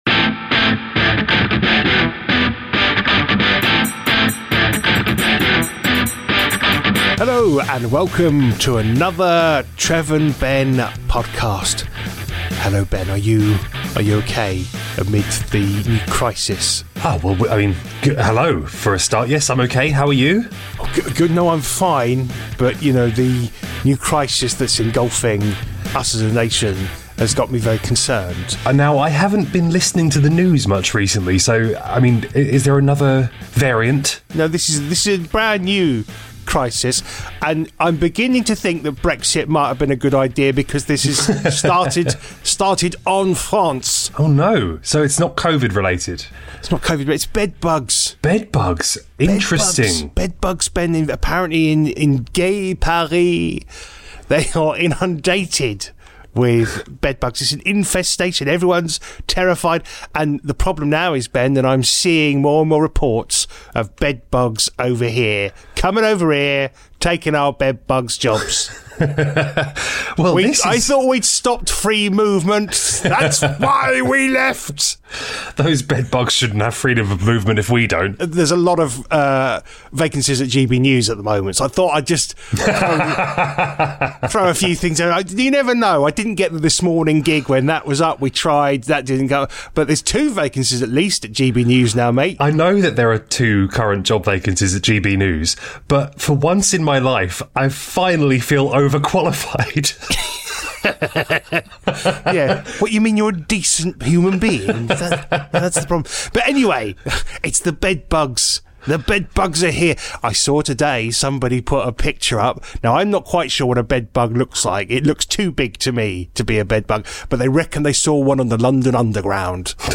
This week we discuss bedbugs, badly sing some advertising jingles from our childhood and confess to knowing nothing about how a car works. Plus, 10 foot spiders, the latest installment in our murder mystery dram and much more!